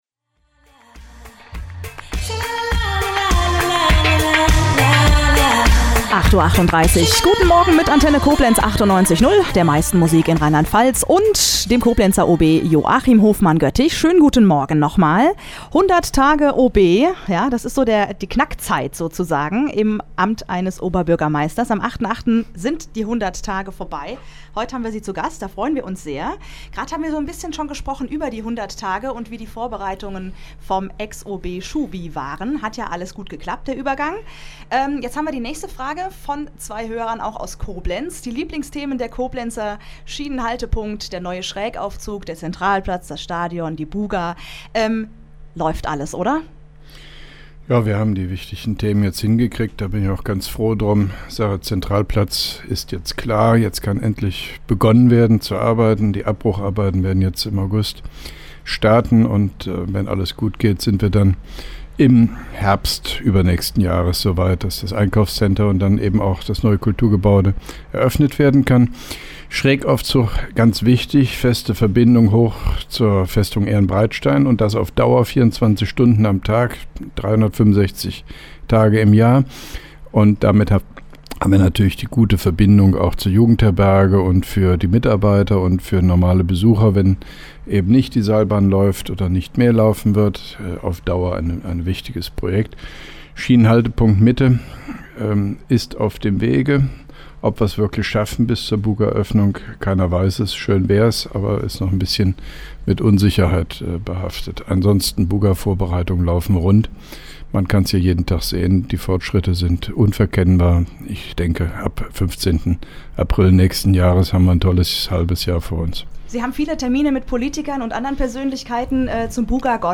(2) Rundfunk-Interview mit dem Koblenzer OB Joachim Hofmann-Göttig zu den ersten 100 Tagen als Oberbürgermeister am 08.08.2010: